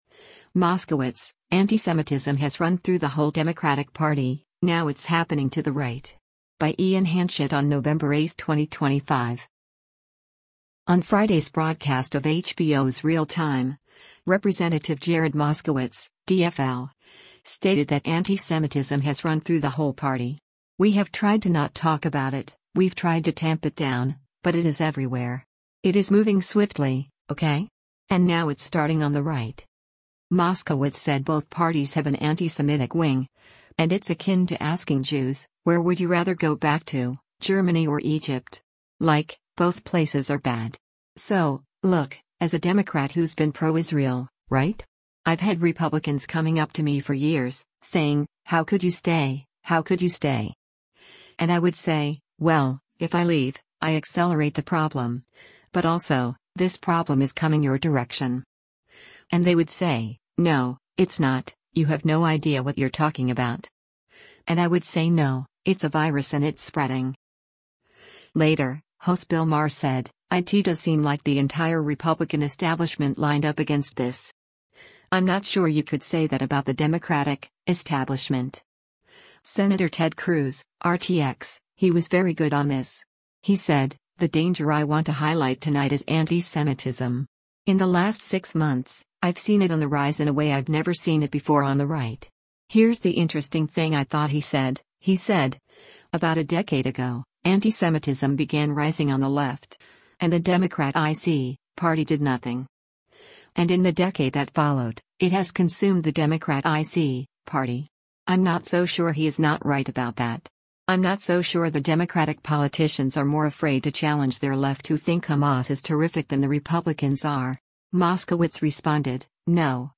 On Friday’s broadcast of HBO’s “Real Time,” Rep. Jared Moskowitz (D-FL) stated that antisemitism “has run through the whole party. We have tried to not talk about it, we’ve tried to tamp it down, but it is everywhere. It is moving swiftly, okay? And now it’s starting on the right.”